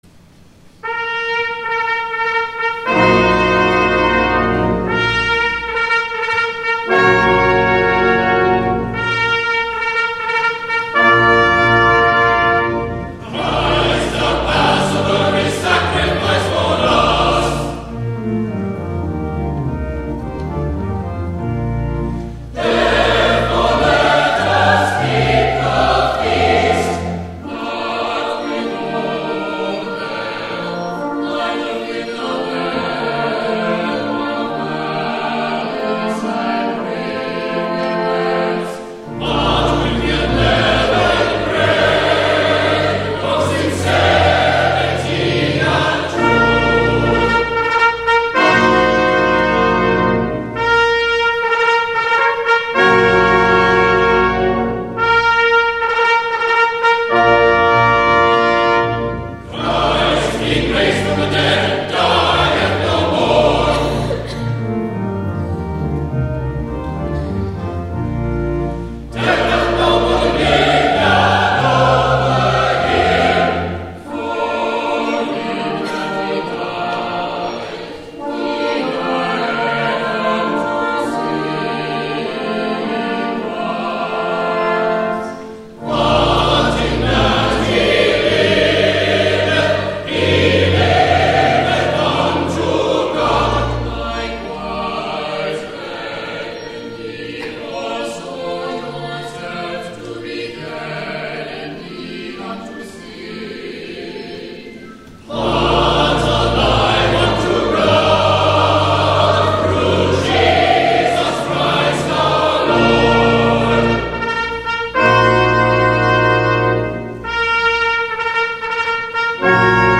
EASTER SUNDAY
THE ANTHEM